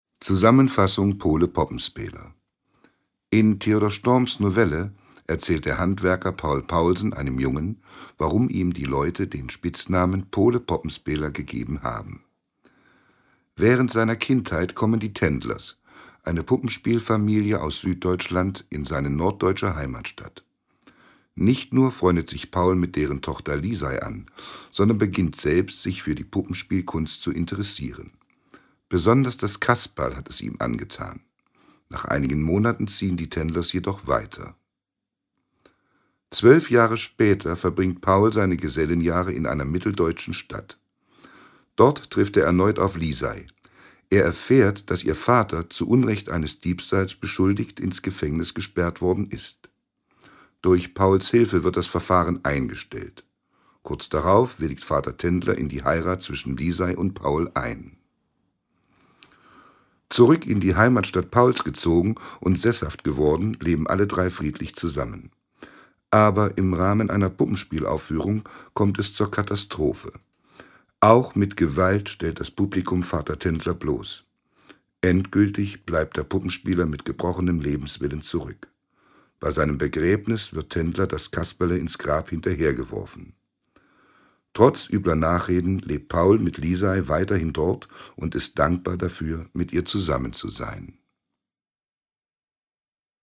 Audioguide zur Ausstellung